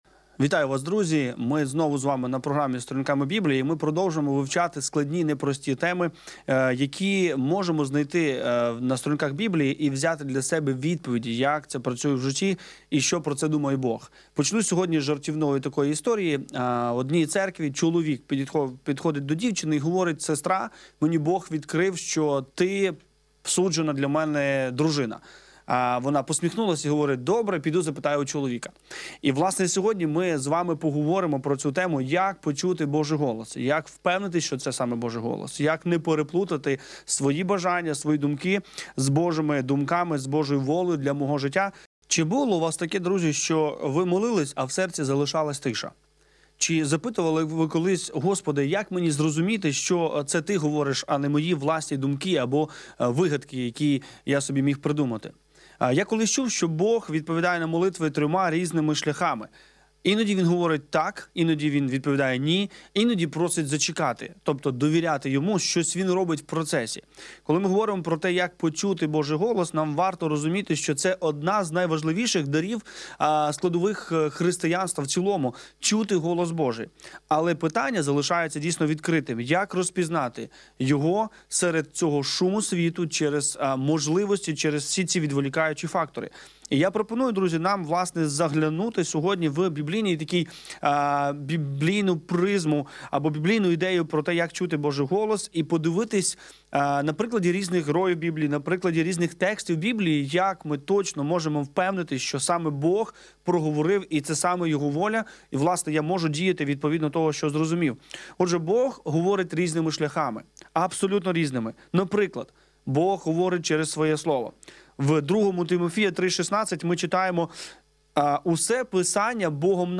Завантажати запис ефіру на тему: Як почути голос Божий ?